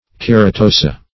Search Result for " keratosa" : The Collaborative International Dictionary of English v.0.48: Keratosa \Ker`a*to"sa\, n. pl.